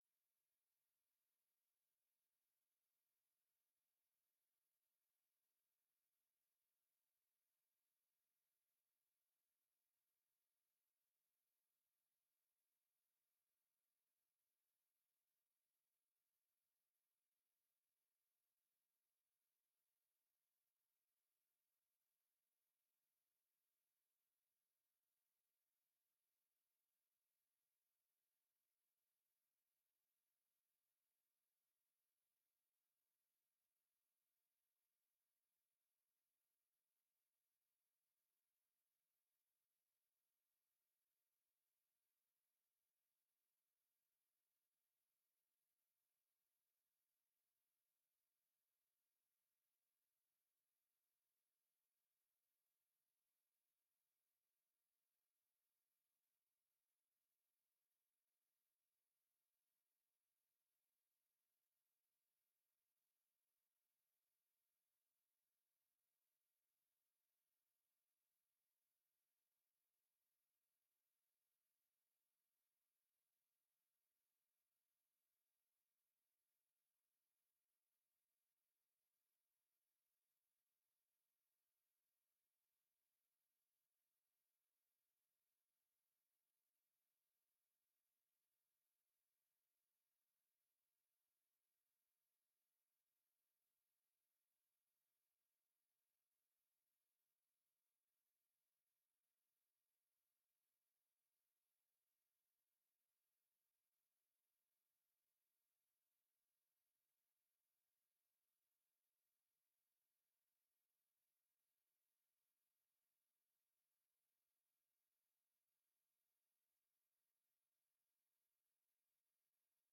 Locatie: Raadzaal
Vanavond is de terugkoppeling over de EUREGIO. Burgemeester Mark Boumans geeft, als lid van het algemeen bestuur en de Euregioraad, eerst een algemene terugkoppeling. Daarna komen de vertegenwoordigers uit de raad aan het woord.